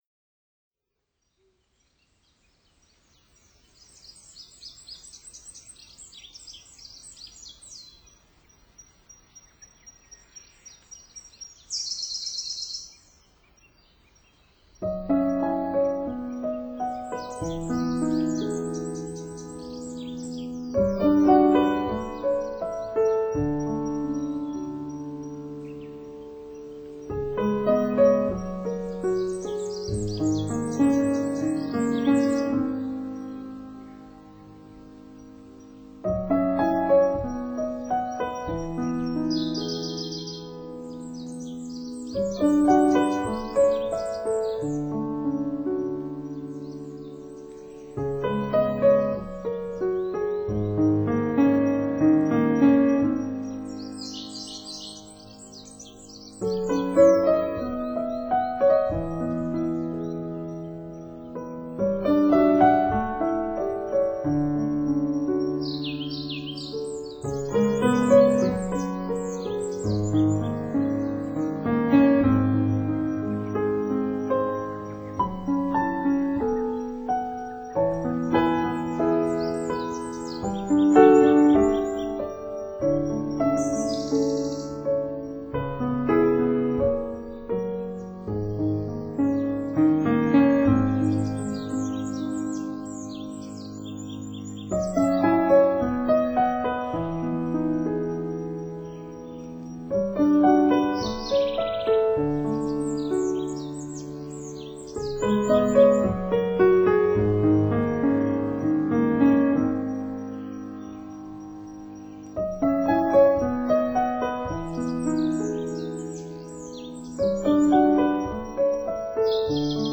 以鋼琴、鍵盤、打擊樂